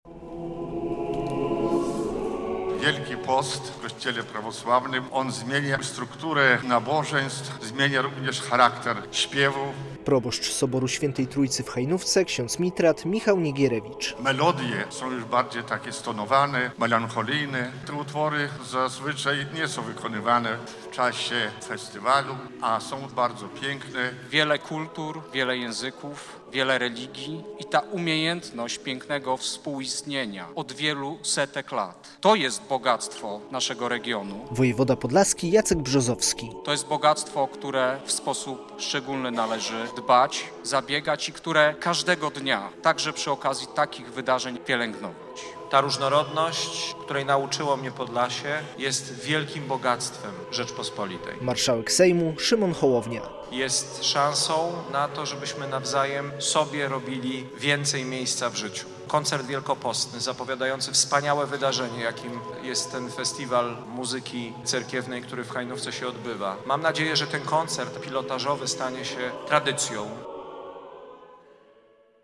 Koncert cerkiewnych pieśni wielkopostnych zorganizowano w niedzielę (23.03) wieczorem w soborze pw. Św. Trójcy w Hajnówce.
Koncert pieśni wielkopostnych zapowiada festiwal Hajnowskie Dni Muzyki Cerkiewnej - relacja